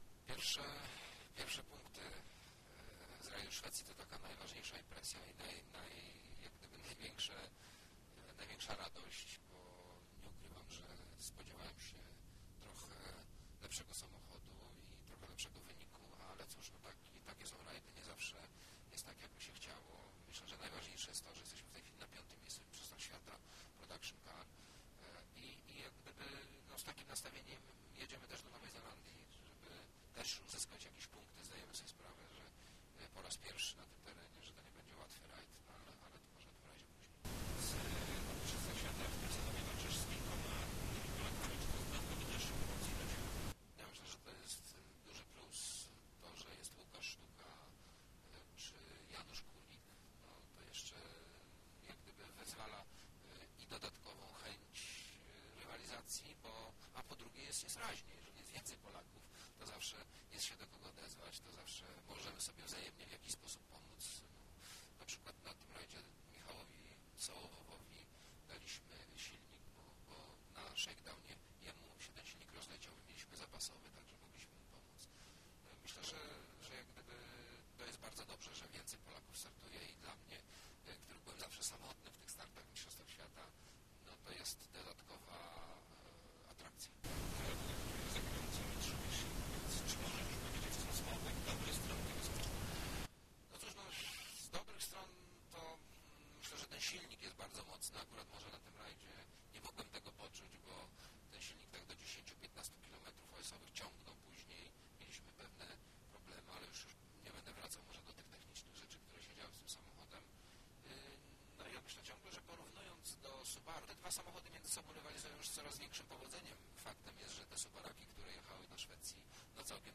holowczyc_wywiad.mp3